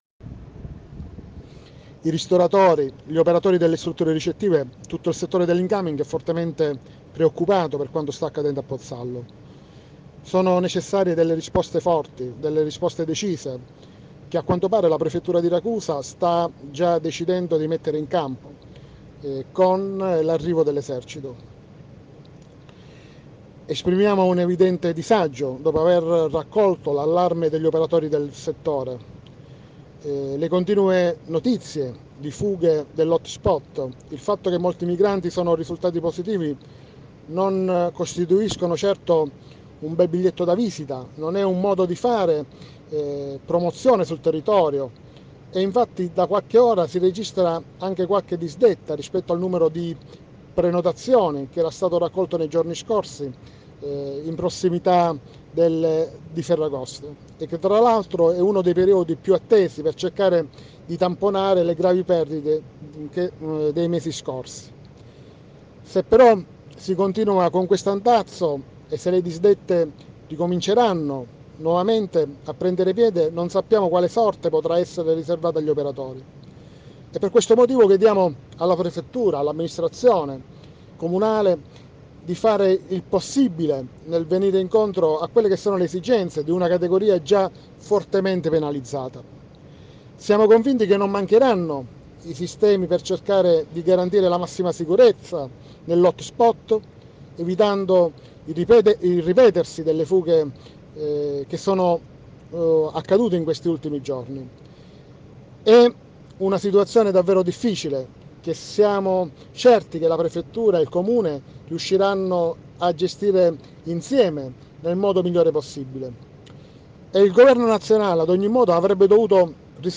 Fughe HotSpot Pozzallo. Confcommercio: Danni e disdette in città. INTERVISTA